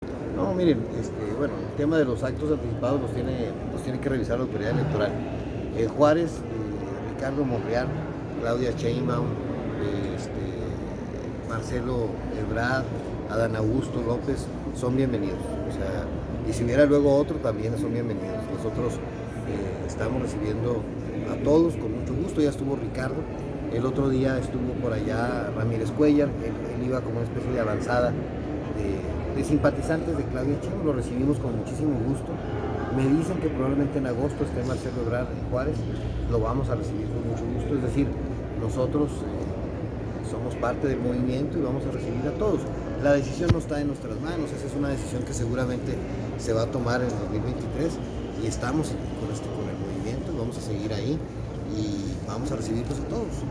Audio. Cruz Pérez Cuéllar, alcalde de Ciudad Juárez.
Perez-Cuellar-sobre-candidatos-de-Morena.mp3